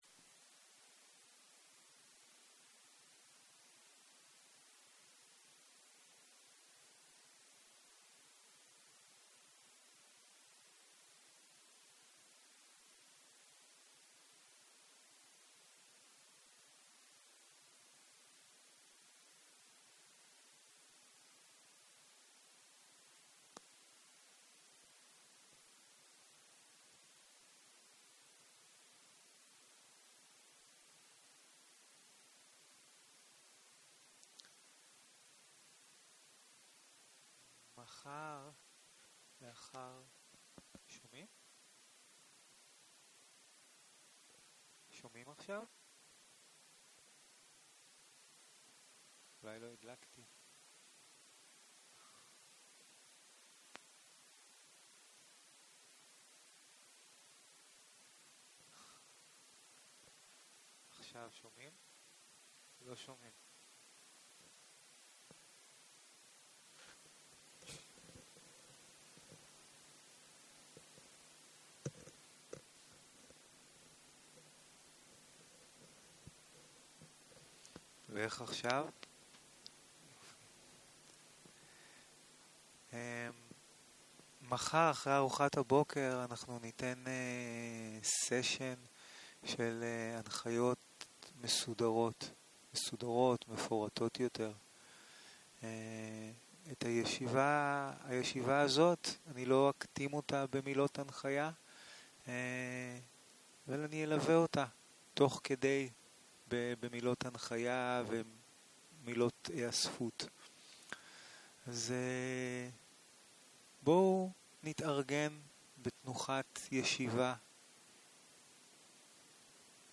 29.03.2023 - יום 1 - ערב - הנחיות מדיטציה - הקלטה 1
Guided meditation שפת ההקלטה